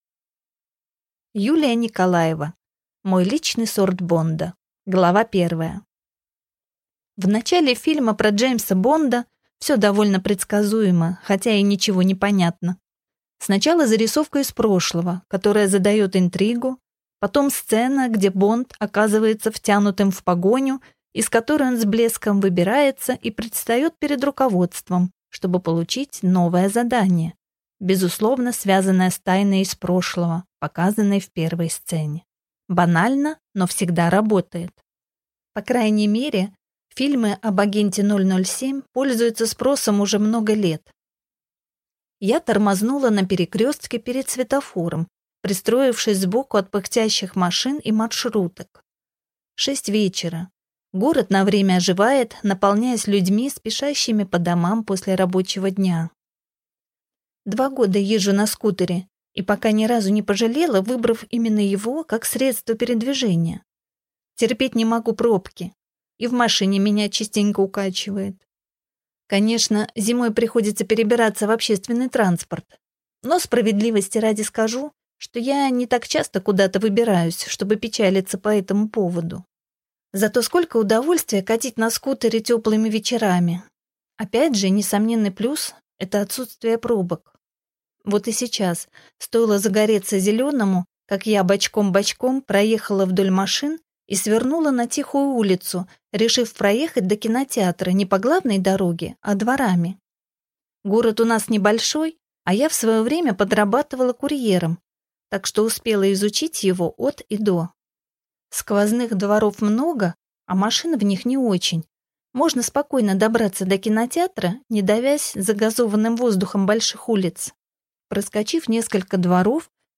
Аудиокнига Мой личный сорт Бонда | Библиотека аудиокниг